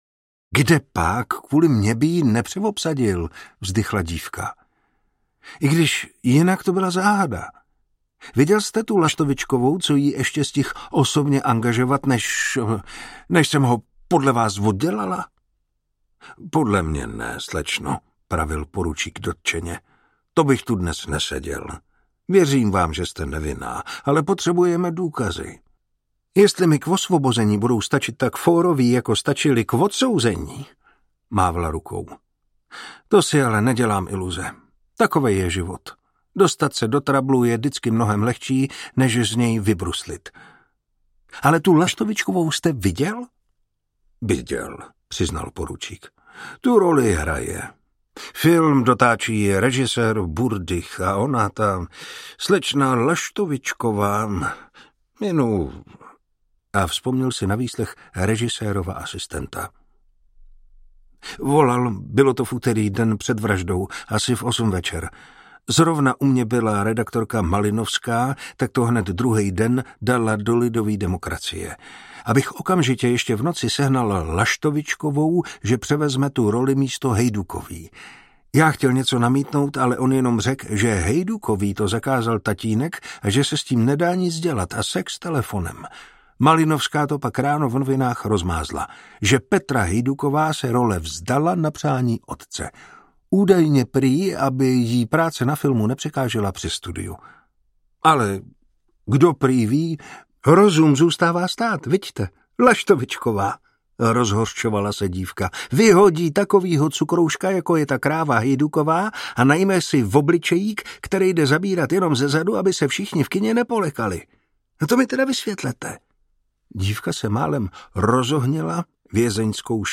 Hříchy pro pátera Knoxe audiokniha
Ukázka z knihy
• InterpretMartin Preiss